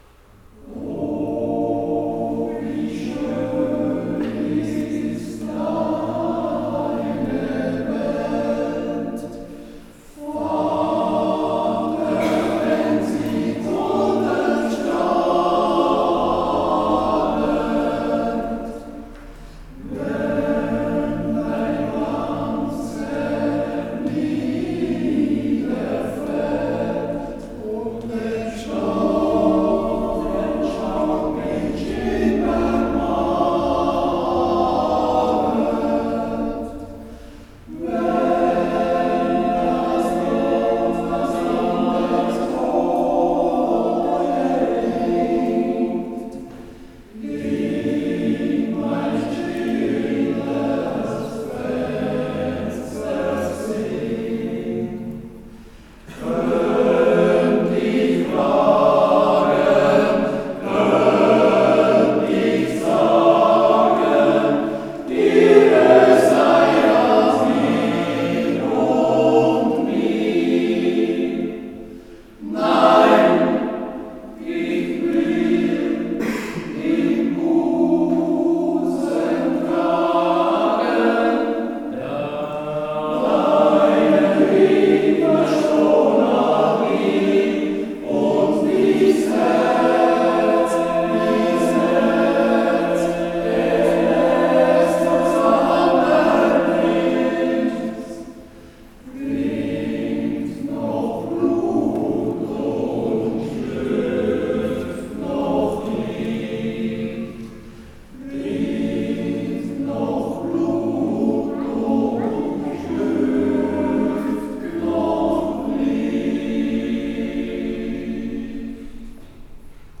Muttertagskonzert 2025